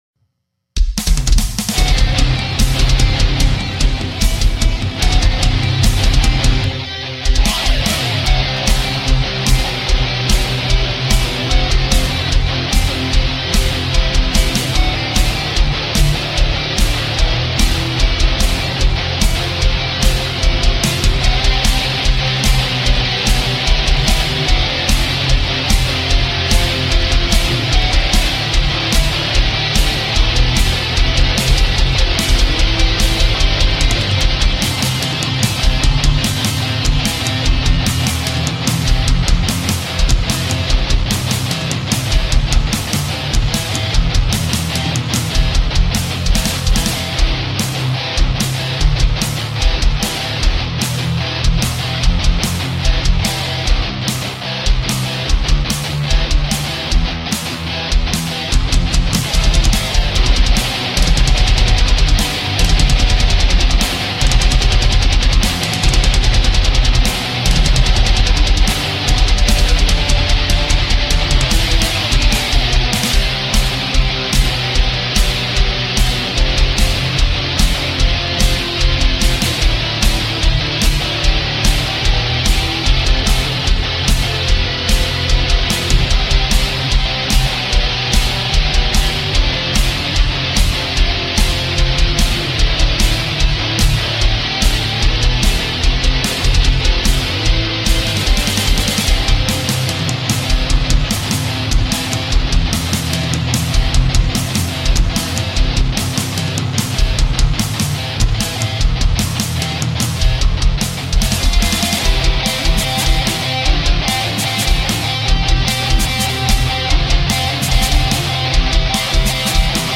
I seriously accidentally the cymbals, brah.